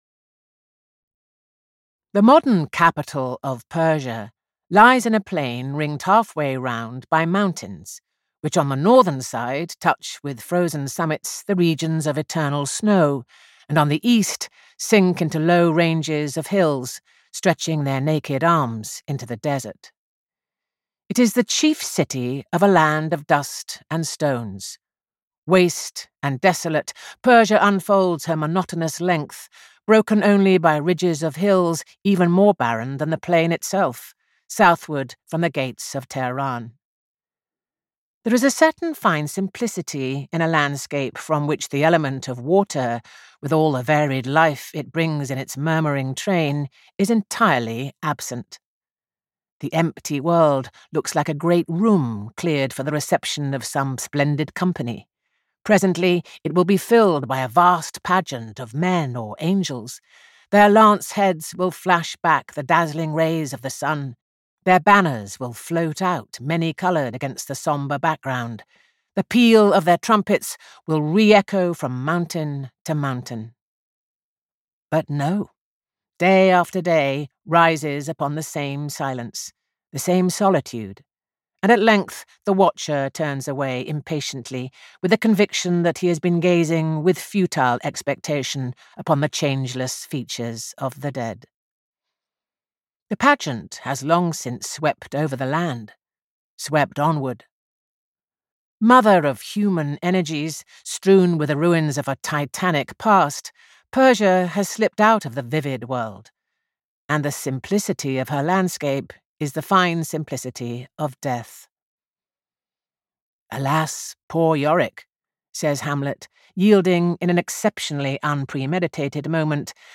Persian Pictures audiokniha
Ukázka z knihy
• InterpretJuliet Stevenson